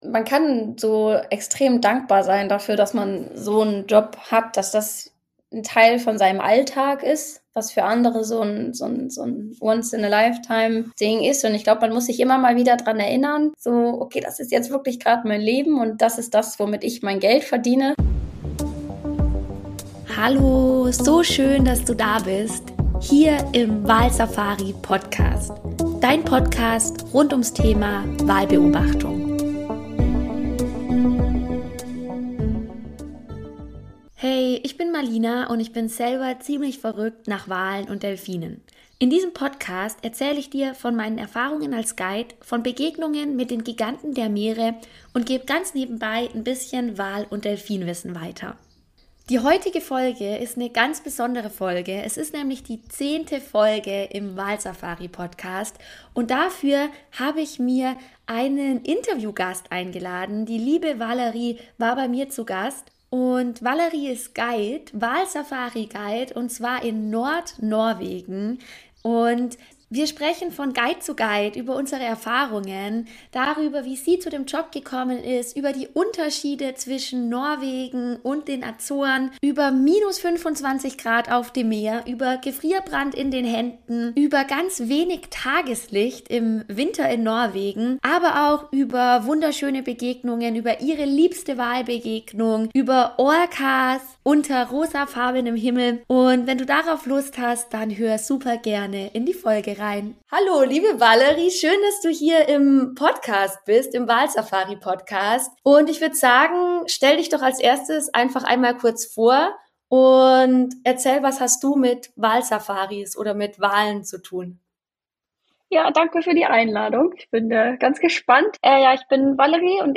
Wir feiern heute gleich zwei Premieren: die 10. Folge und unsere allererste Interviewfolge.